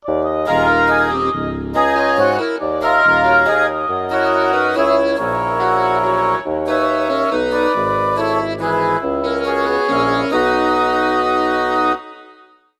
Quinteto de viento madera. Breve pieza (sonido sintético)
trompa
clarinete
fagot
flauta
oboe
quinteto